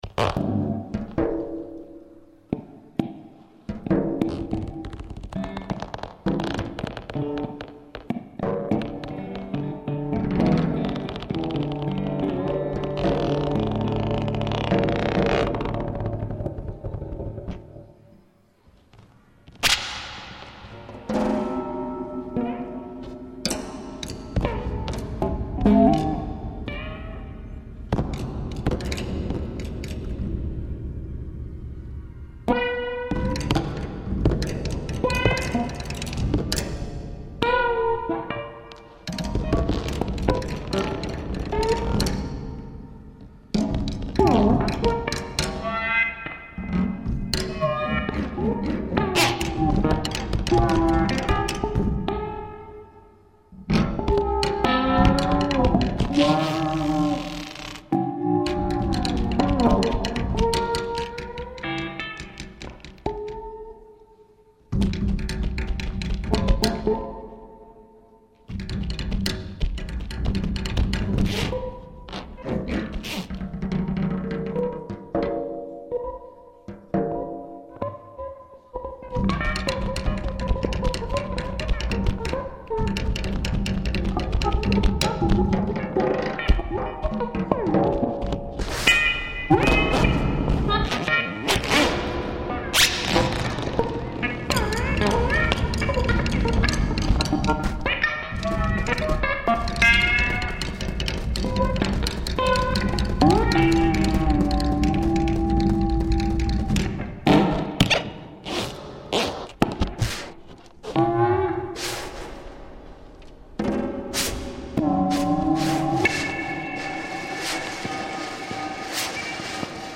Konzert für elektroakustisches Duo und 100 Kopfhöhrer.
Extended-Guitar, Elektronik, Gadgets
EDV-Schlagzeug und Sensoren
sound: excerpt konzert treibhaus innsbruck juni 2002